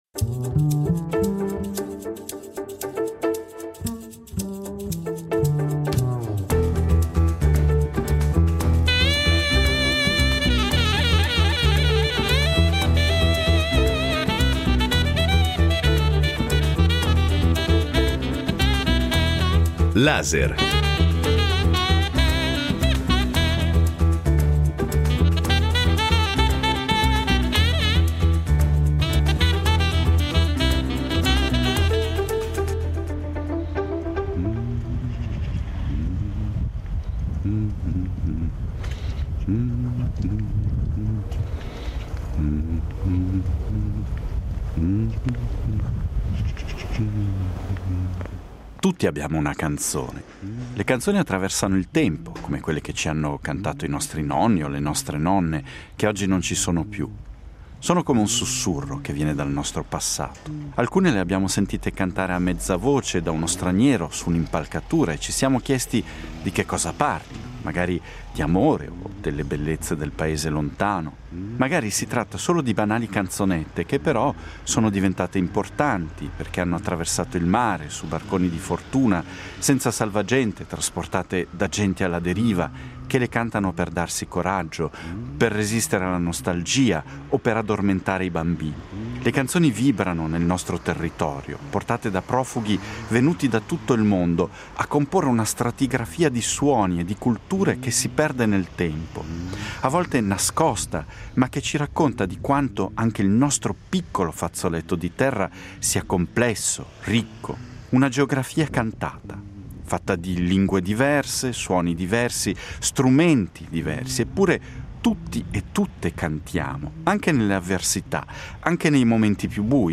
Una geografia cantata in due puntate in cui si racconta di come le canzoni viaggino - a differenza degli esseri umani - libere nel mondo e di come si facciano strada fino alle nostre orecchie usando l'alfabeto delle emozioni, per il quale nessuno è profugo e tutte e tutti hanno una voce propria, un corpo e qualcosa di importante da raccontare.
Ad emergere è allora una geografia cantata fatta di lingue, ritornelli, racconti, che arrivano - spogliati di pregiudizi - alle nostre orecchie emotive e ci interrogano sulla nostra natura di viaggiatori, per scelta o per necessità.